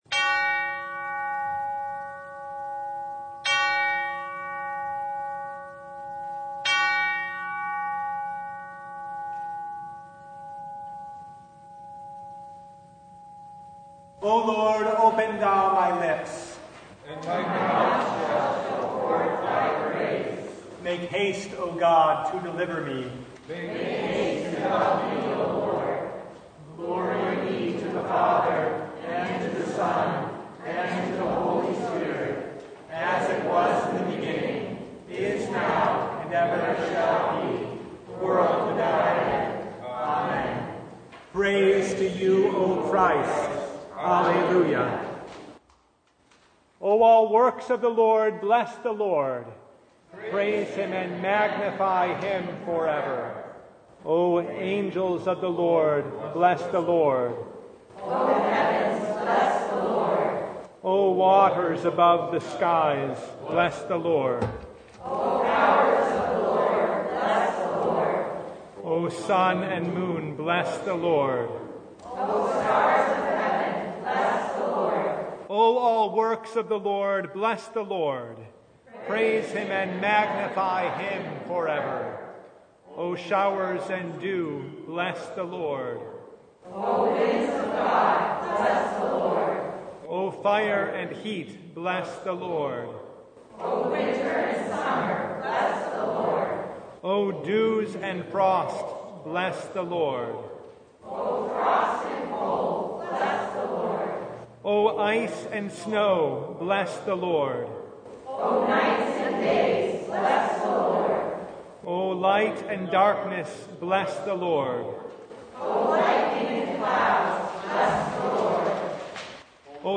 Thanksgiving Eve Service (audio recording)
Psalm 67:1-7 Service Type: Thanksgiving Eve “God shall bless us”